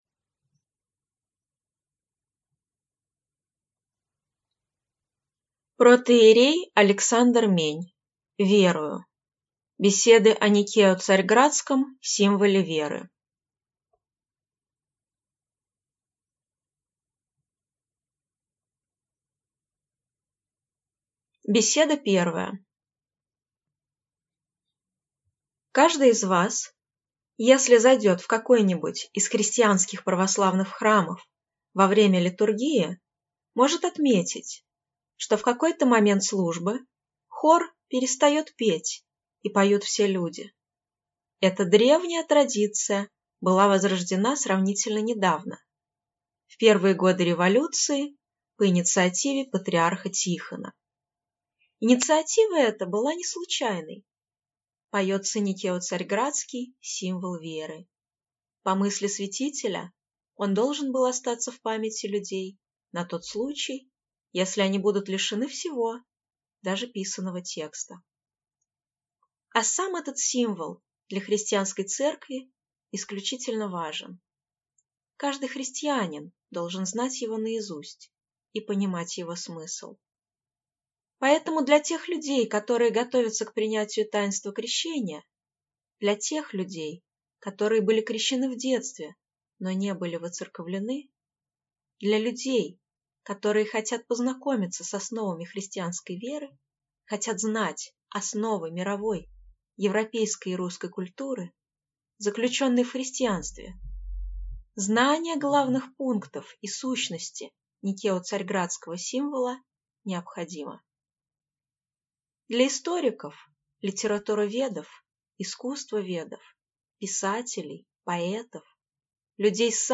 Аудиокнига Верую… Беседы о Никео-Царьградском Символе Веры | Библиотека аудиокниг